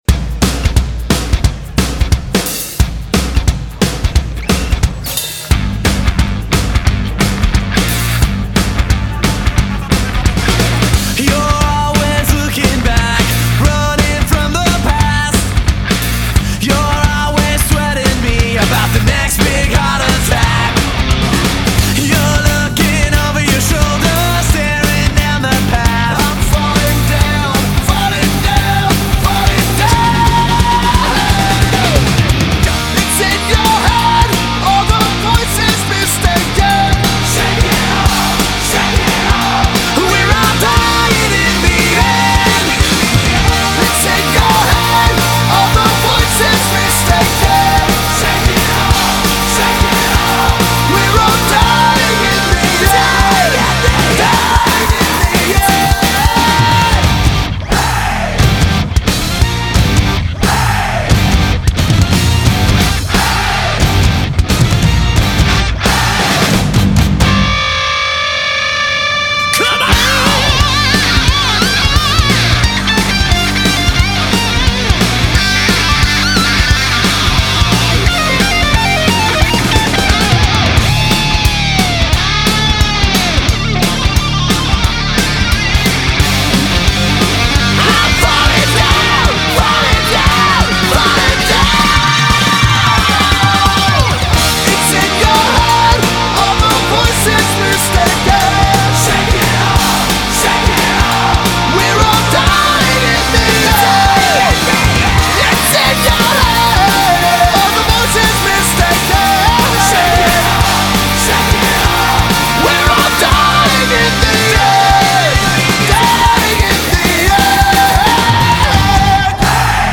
BPM177
Audio QualityPerfect (High Quality)